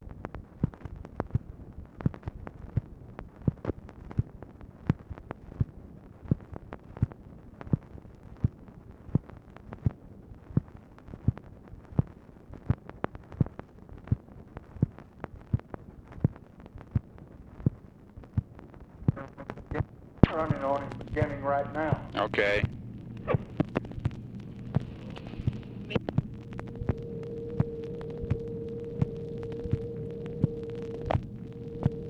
Conversation with MCGEORGE BUNDY, May 2, 1965
Secret White House Tapes